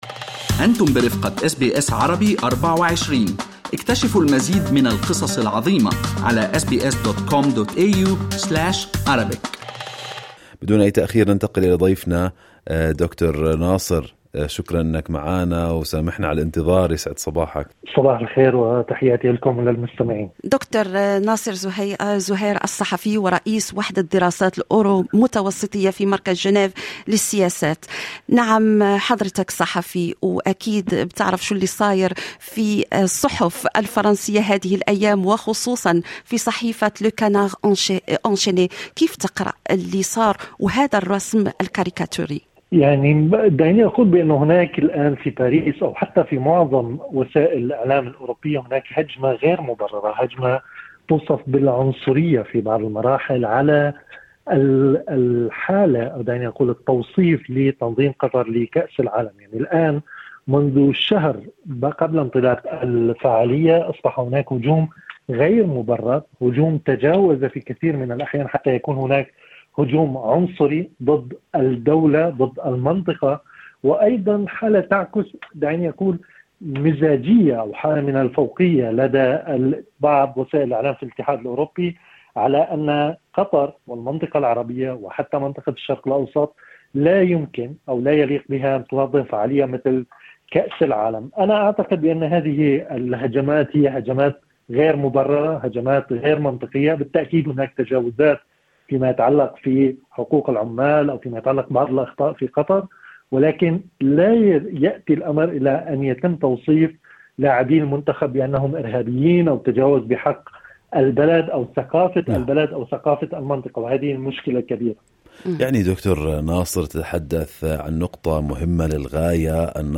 وفي هذا الموضوع، تحدثنا إلى الصحفي